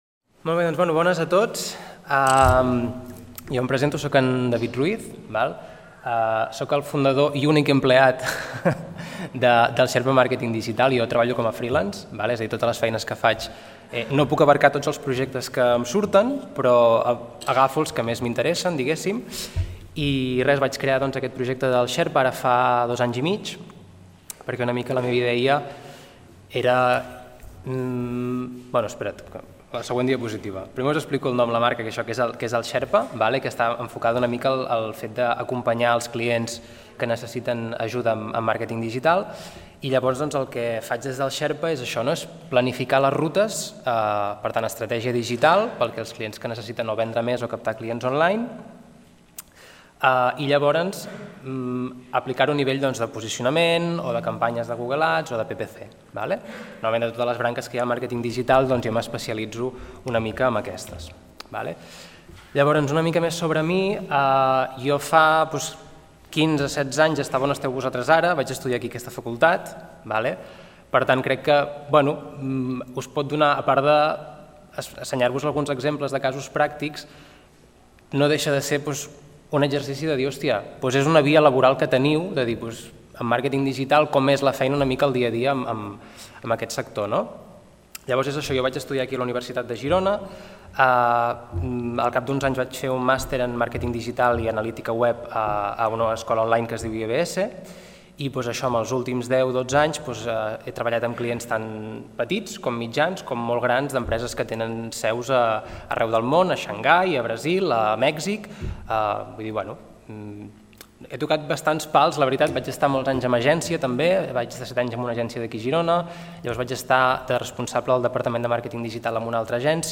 La conferència tracta sobre el desenvolupament de projectes destacats vinculats al Màrqueting digital i la presentació de casos en què s'hagi potenciat el SEO i SEM a partir de diverses tipologies de campanyes aplicades, així com el software utilitzat principalment en cada cas, els KPIs de les campanyes i indicadors de rendiment.